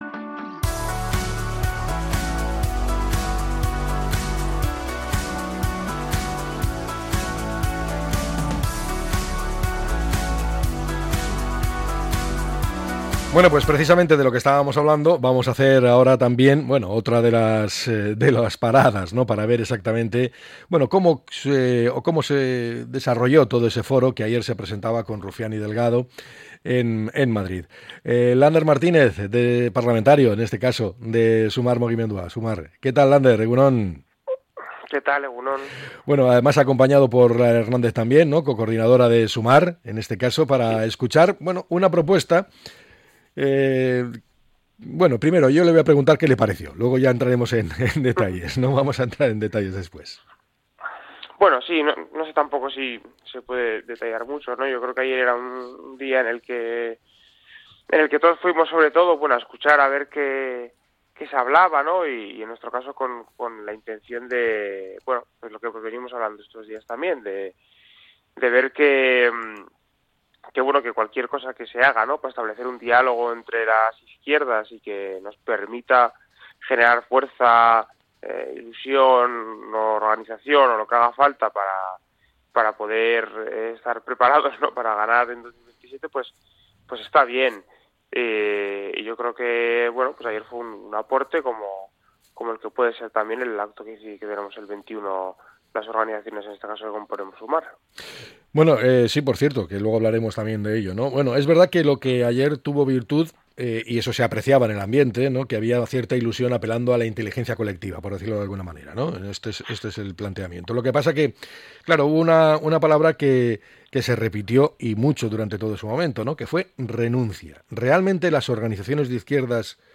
El diputado de Sumar ha analizado la actualidad política en pleno debate sobre la unidad de la izquierda